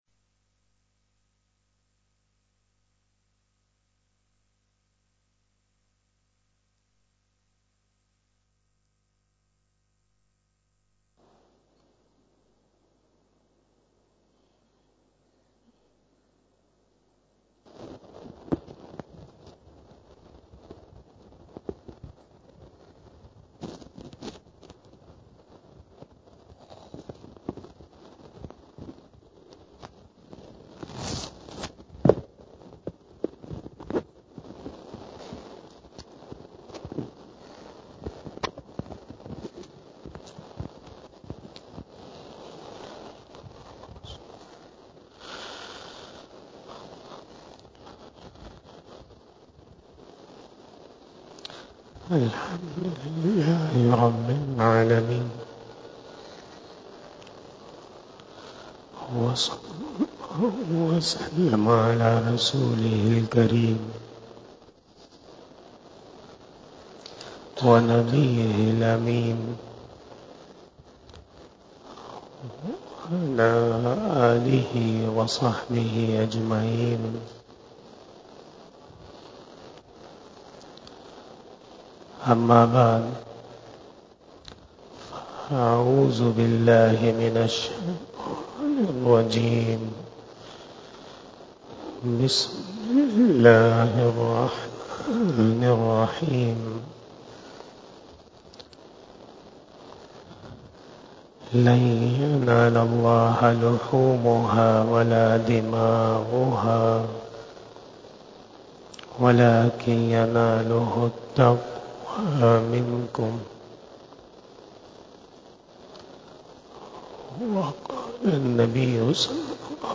25 BAYAN E JUMMAH 23 June 2023 (04 Zul Hajjah 1444HJ)
02:08 PM 201 Khitab-e-Jummah 2023 --